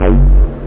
Amiga 8-bit Sampled Voice
303-spacebass.mp3